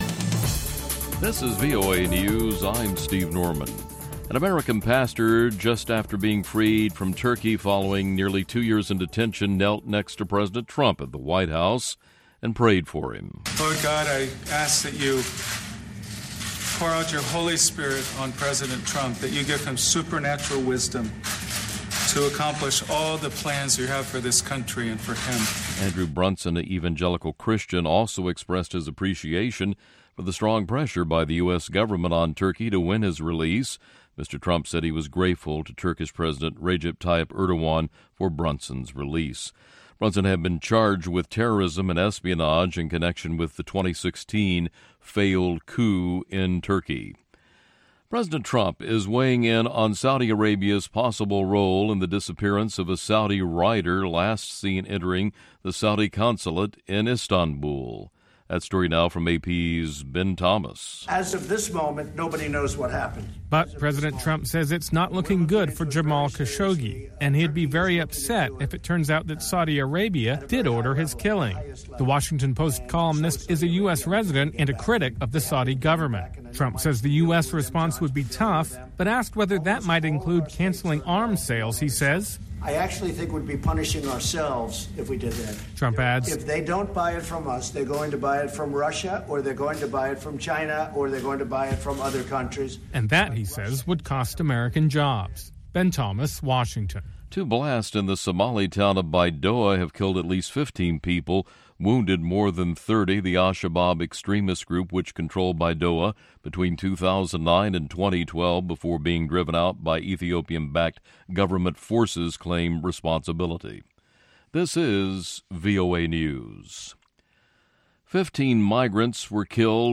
In this episode: a high-energy lineup of Ethiopian rock, Afrobeats, Mande folk, Bongo Flava, classic Tuku from Zimbabwe and more.